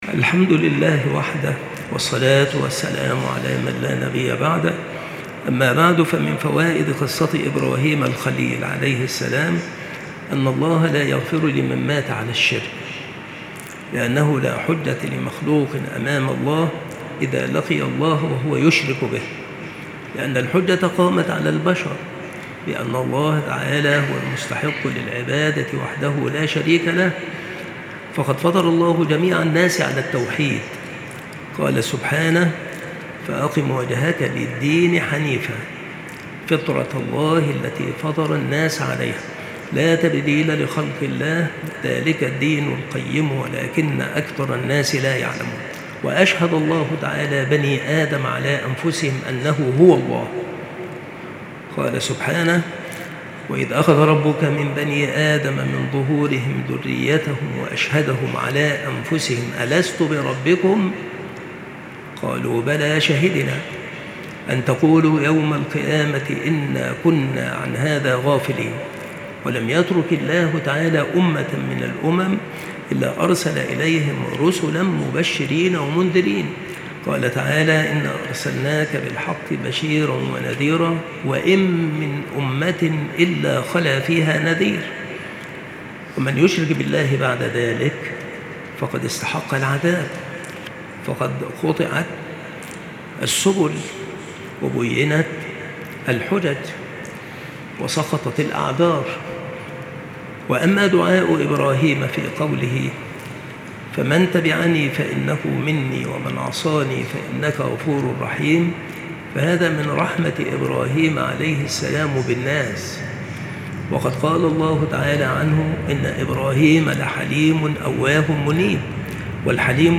بالمسجد الشرقي - سبك الأحد - أشمون - محافظة المنوفية - مصر